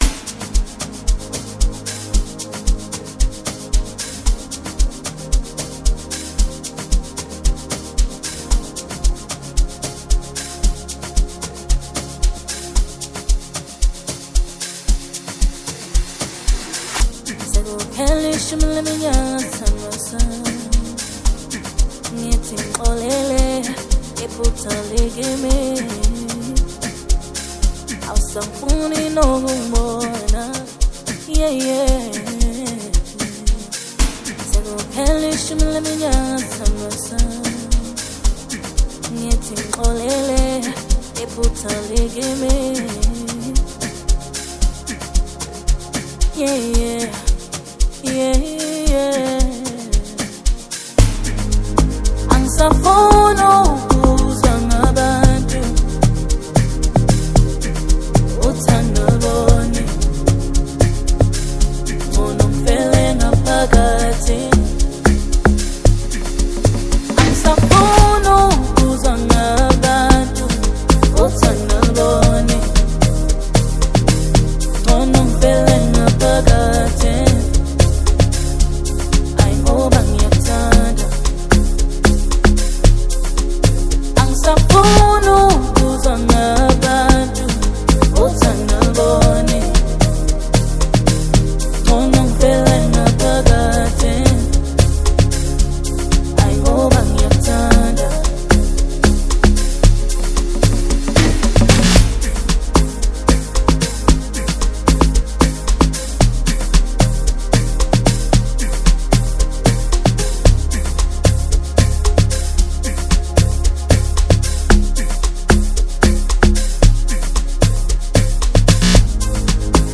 soul-stirring vocals, and emotive production